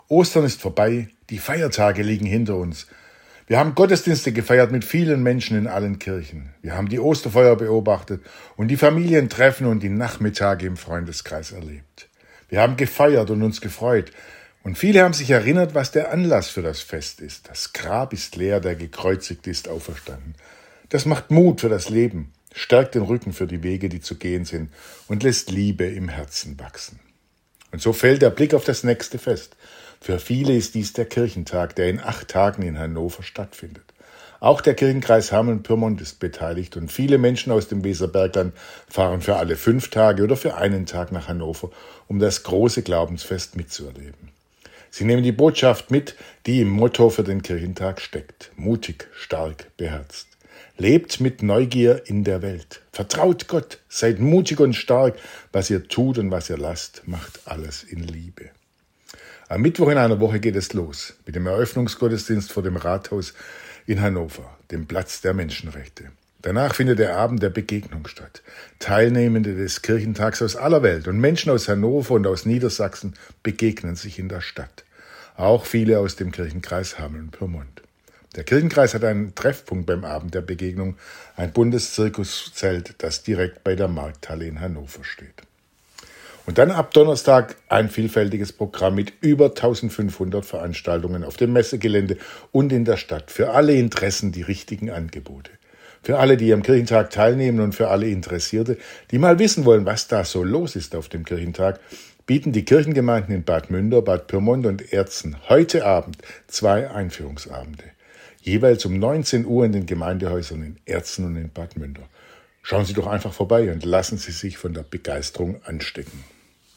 Weserbergland: Radioandacht vom 22. April 2025